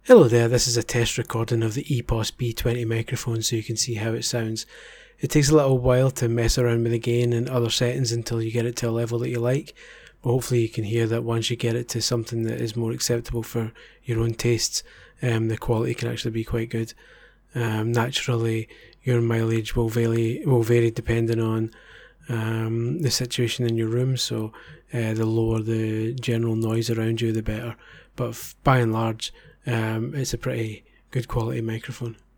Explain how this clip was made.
This $200 mic delivers great quality sound but doesn’t make it easy for you… EPOS-B20-test-recording.mp3